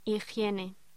Locución: Higiene